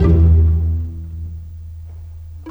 Rock-Pop 09 Pizzicato 07.wav